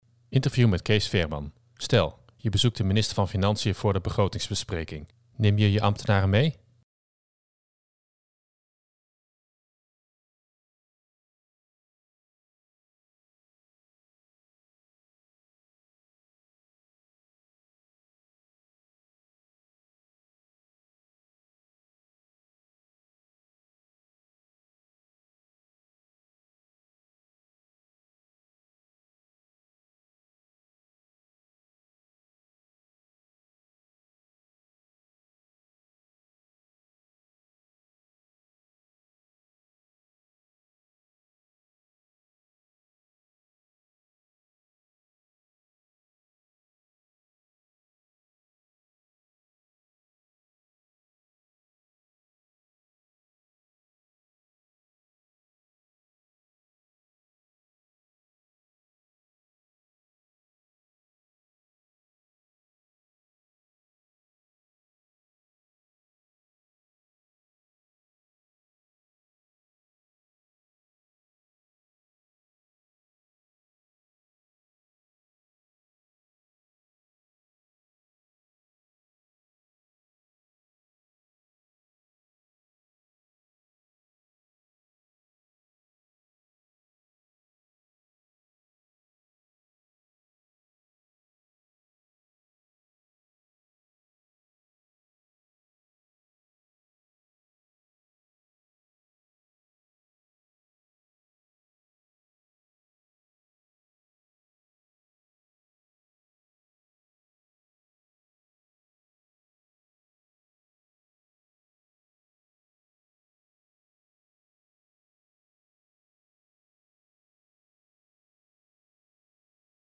Interview met Cees Veerman